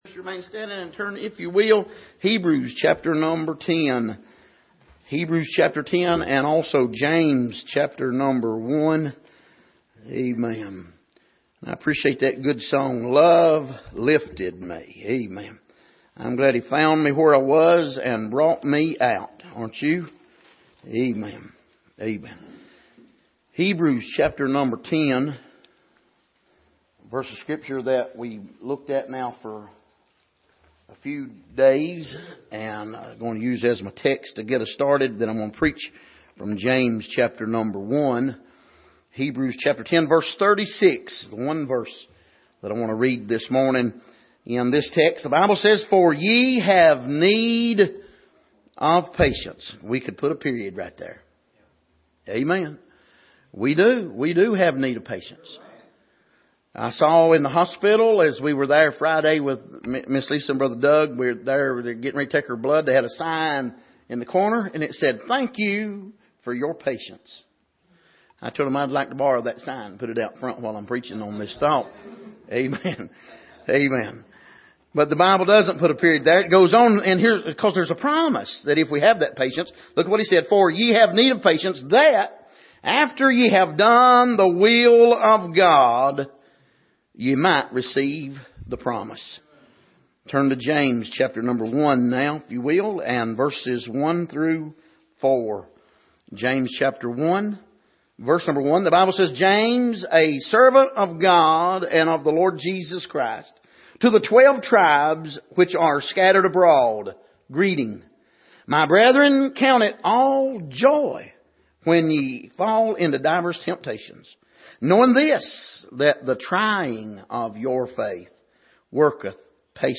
Passage: Hebrews 10:32-39 Service: Sunday Morning